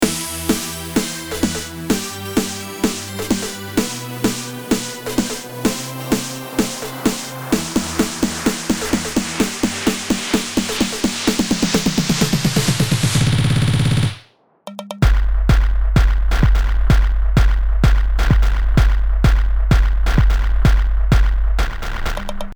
Und hier mal etwas anderes ohne Limiter/EQ/Compressor. Leise gemixt und gebounced mit normalisieren Leider kenne ich keinen der Mastern kann.
Anhänge Ungemastered-recordings.mp3 885,6 KB · Aufrufe: 311